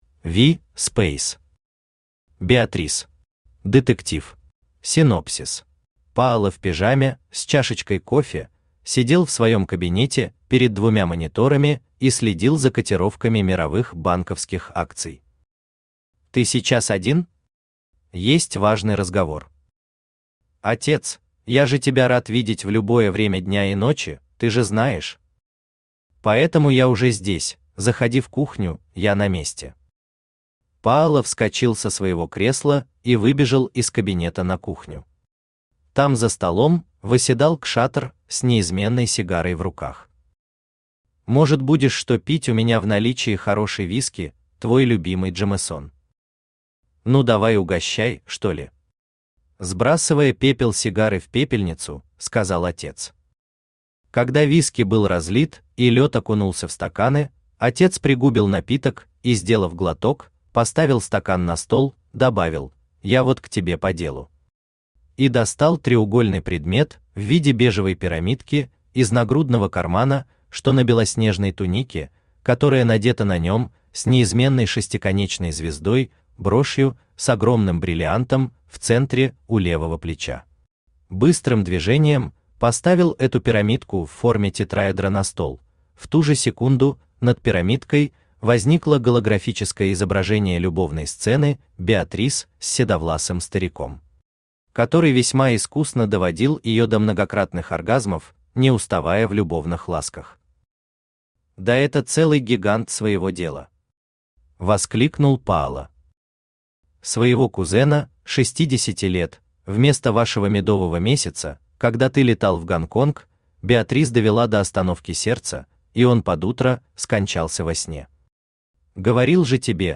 Детектив Автор V. Speys Читает аудиокнигу Авточтец ЛитРес.